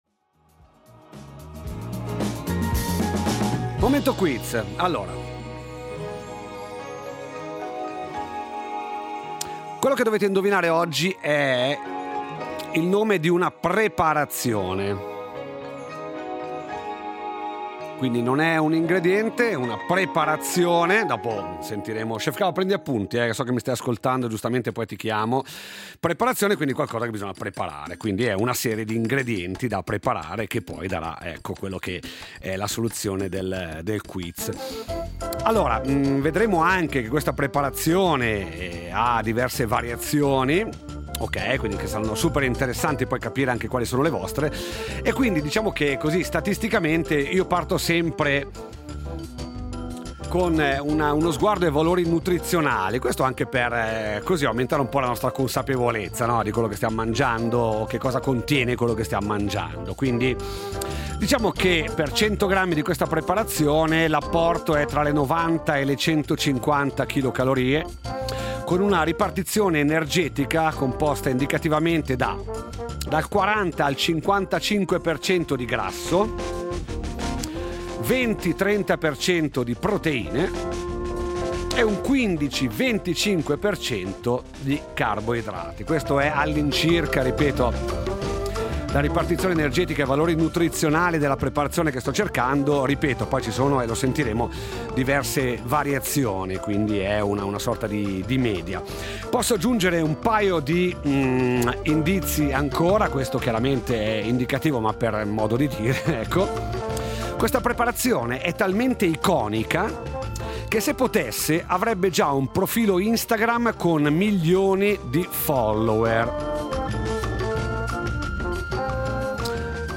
Il quiz radiofonico con in palio un grembiule loggato Rete Tre